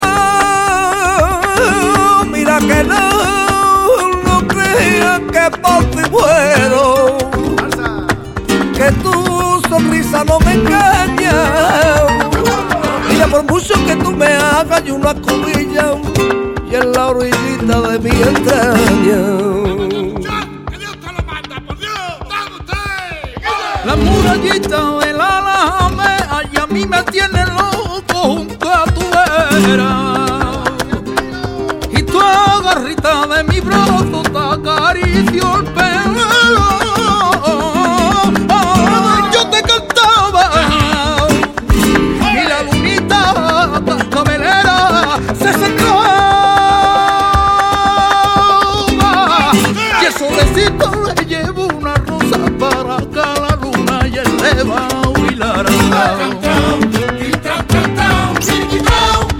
Buleria.